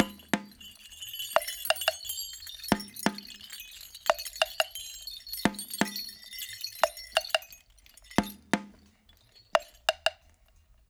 88-PERC3.wav